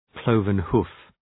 Προφορά
cloven-hoof.mp3